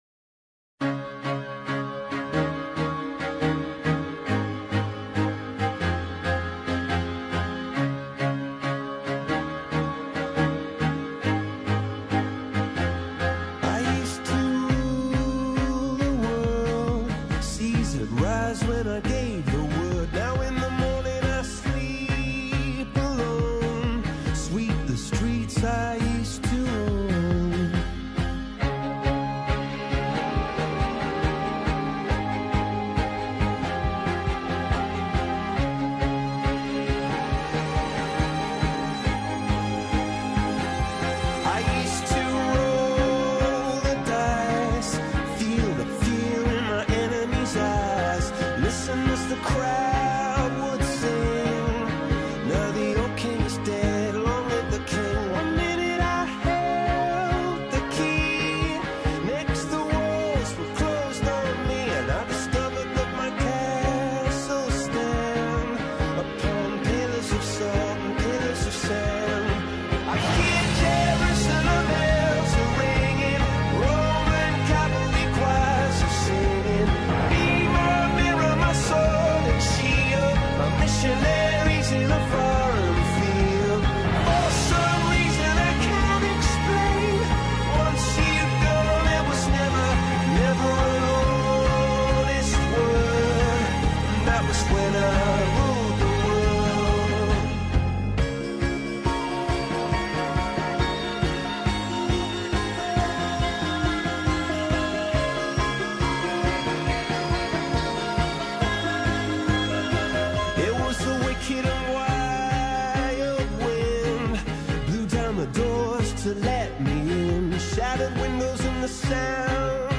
British rock band